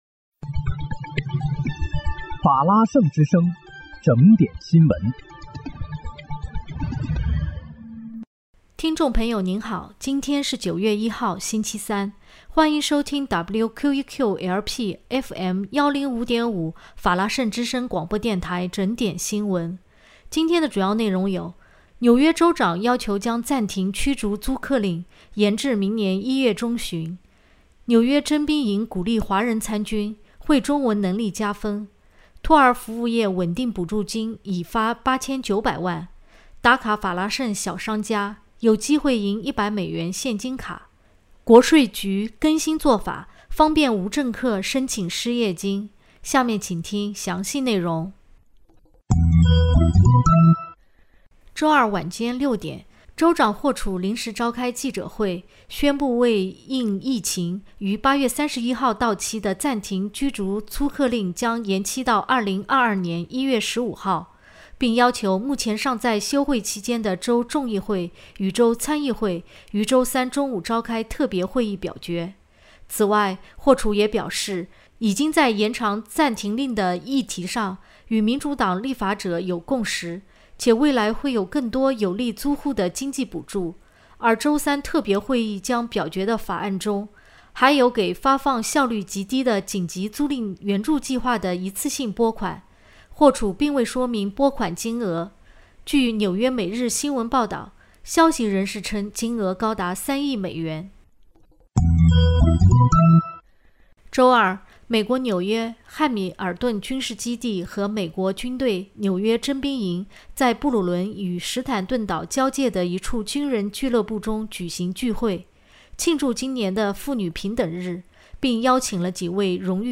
9月1日（星期三）纽约整点新闻